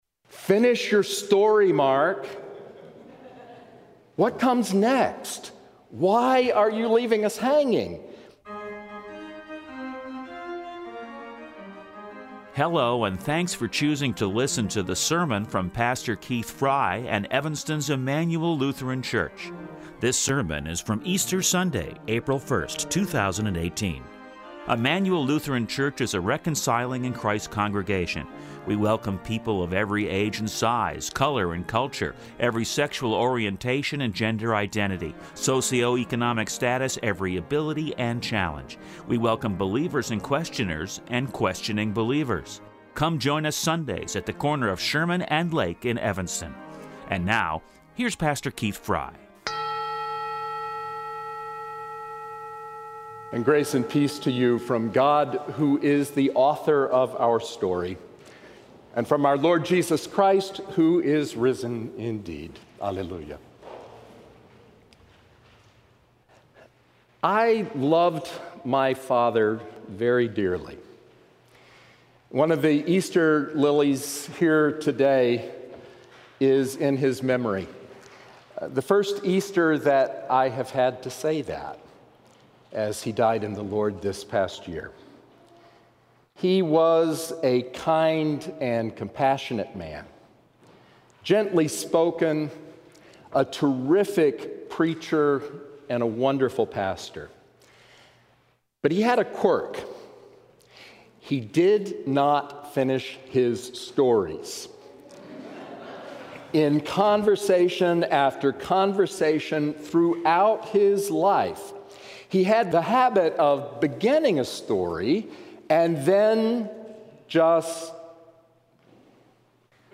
Easter-Sermon-Apr-1-2018.mp3